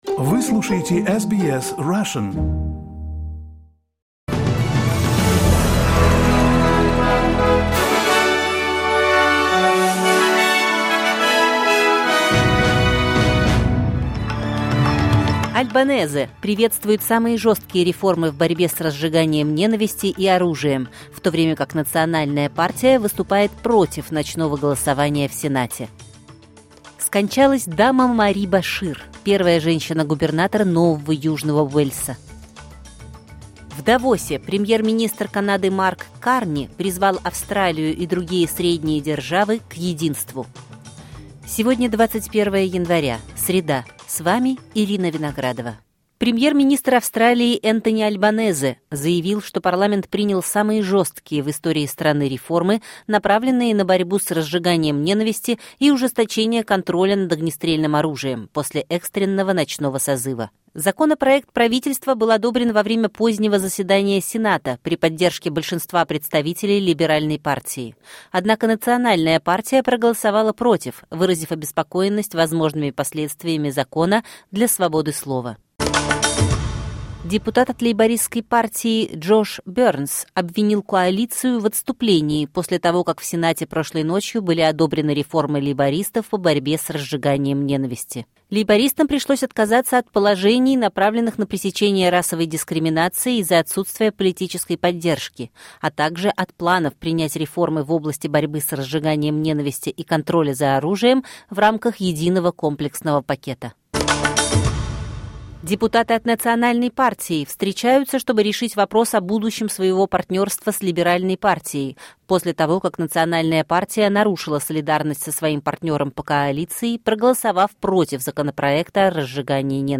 Новости SBS на русском языке — 21.01.2026